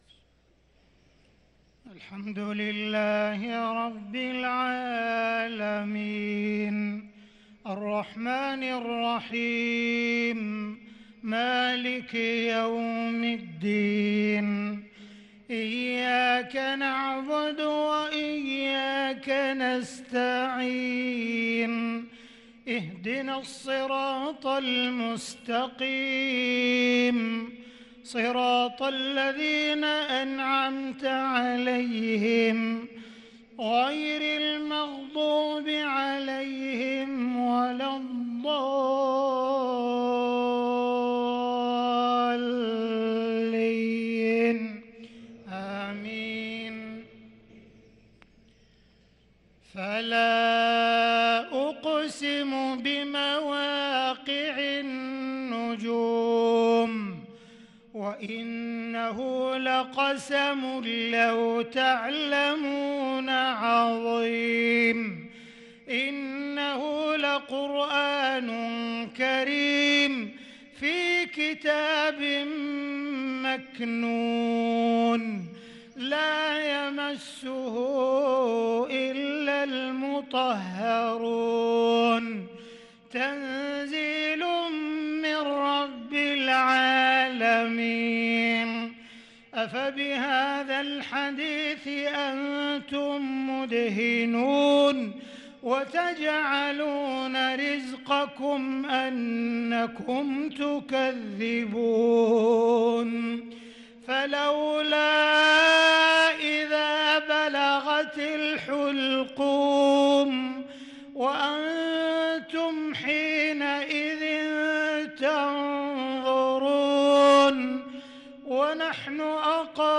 صلاة المغرب للقارئ عبدالرحمن السديس 11 رمضان 1443 هـ
تِلَاوَات الْحَرَمَيْن .